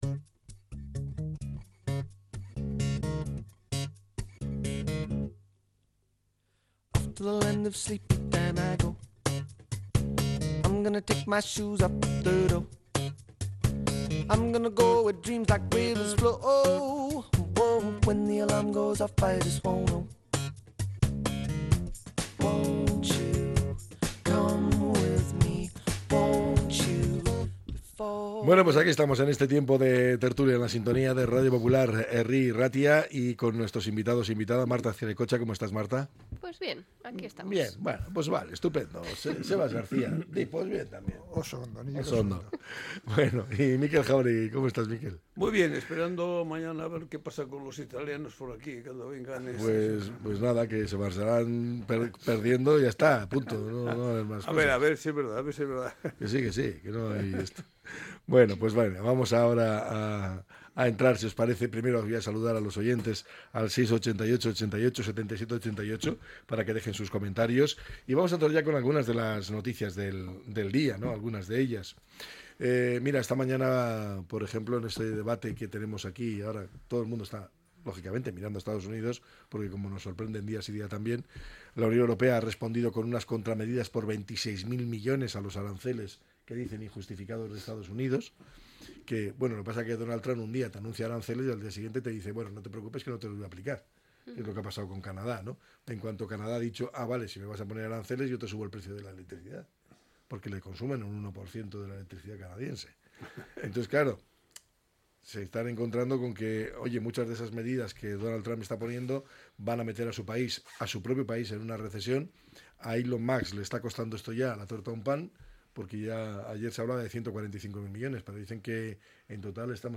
La tertulia 12-03-25.